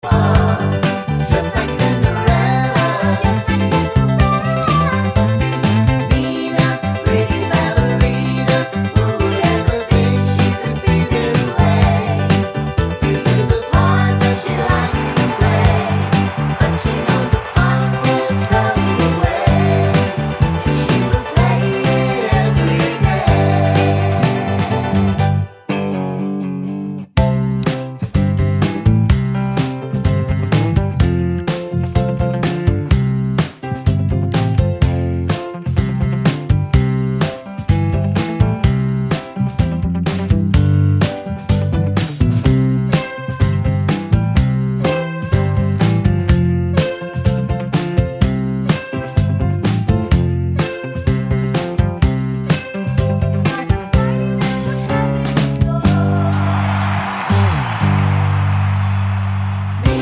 Clean Intro Version Pop (1970s) 3:00 Buy £1.50